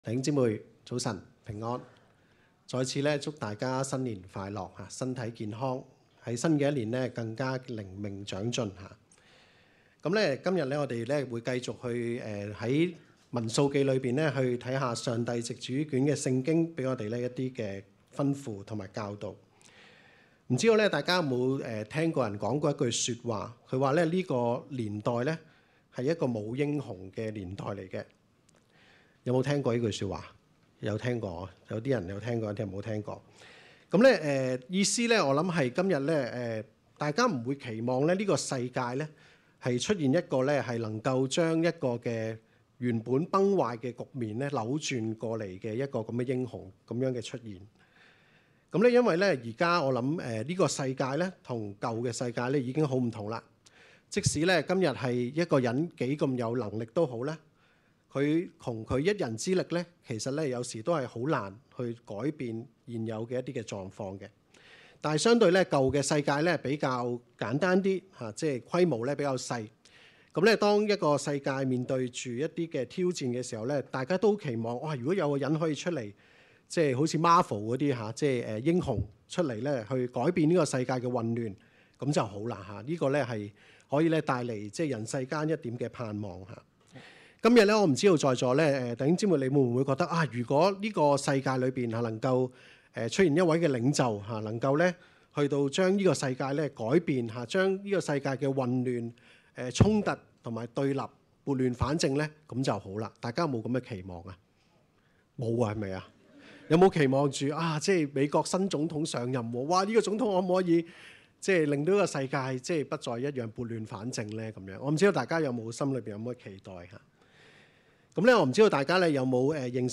证道集